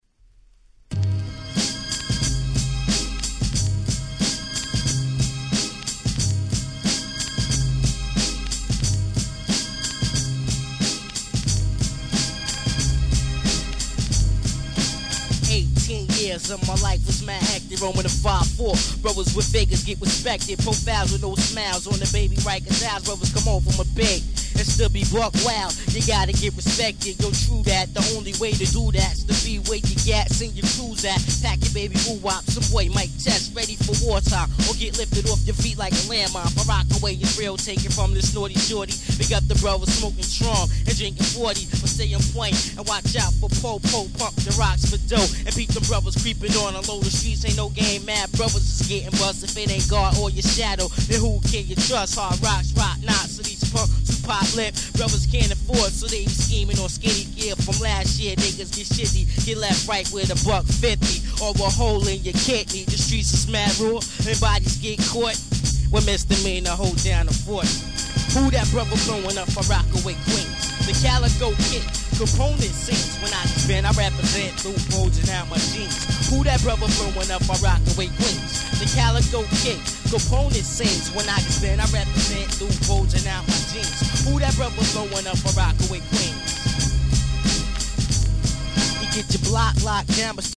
94' Nice Underground Hip Hop !!